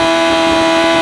Sfx / Engines